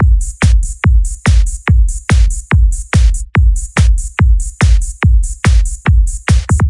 Psy Beat Loops QPA Oldies " Psy Beat Meta Sub Bass
描述：在这首歌曲中，我们可以看到，有很多人对这首歌曲感兴趣。贝斯几乎就像一个听起来很深的打击乐器
标签： 次低音 循环 电子 psytrance 舞蹈节拍 舞蹈 迷幻恍惚 打环 全上 节拍 恍惚节拍
声道立体声